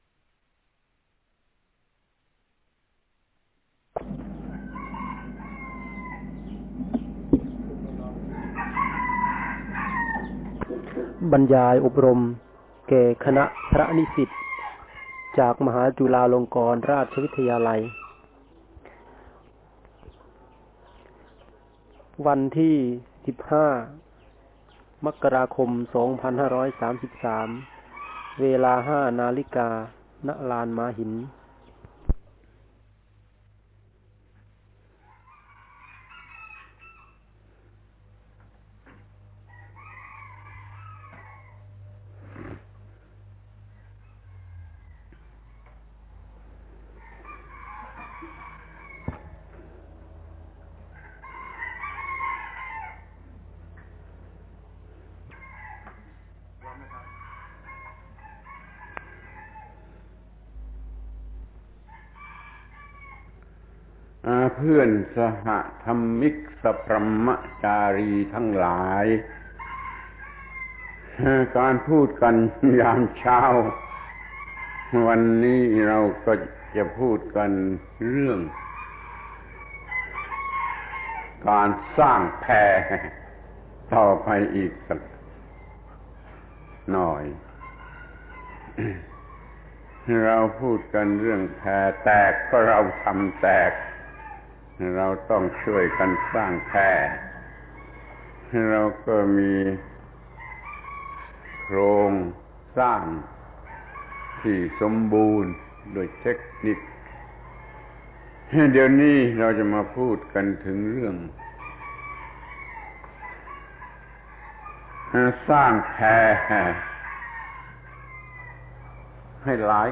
พระธรรมโกศาจารย์ (พุทธทาสภิกขุ) - บรรยายแก่พระนิสิตมหาจุฬาลงกรณ์ราชวิทยาลัย ปี 2533 ครั้งที่ 4 เรื่อง สร้างแพให้หลายขนาดกันดีกว่า